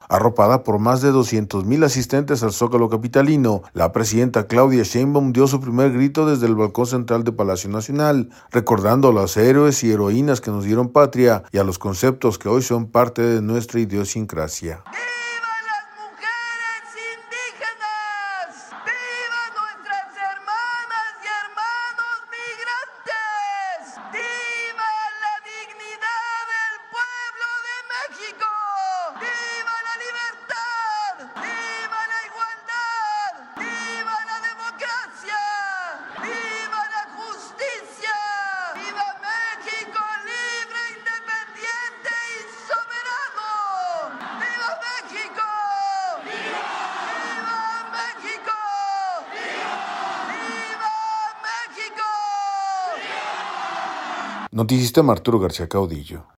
Arropada por más de 200 mil asistentes al zócalo capitalino, la presidenta Claudia Sheinbaum dio su primer grito desde el balcón central de Palacio Nacional recordando a los héroes y heroínas que nos dieron patria y a los conceptos que hoy son […]